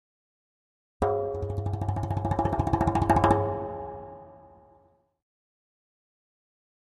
Drums Percussion Danger - Fast Drumming On A Thin Metal Percussion 1